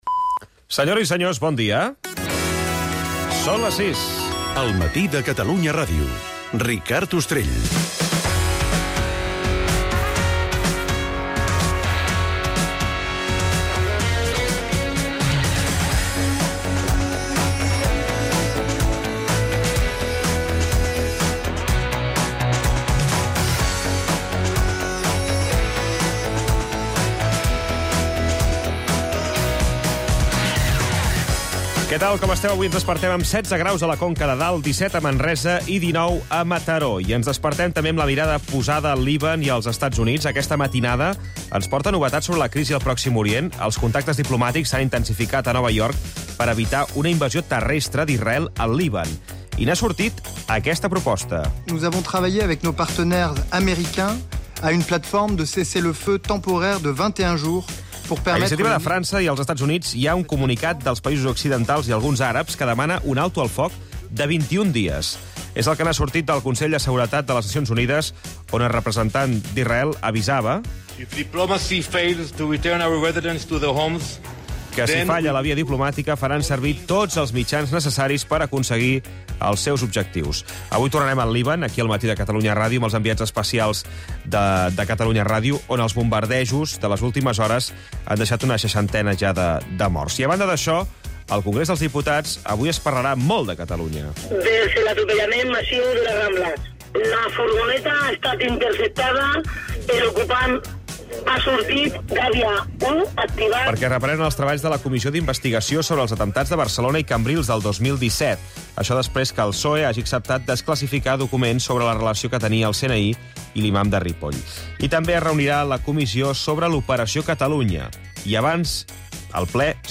El mat, de 6 a 7 h (hora informativa) - 26/09/2024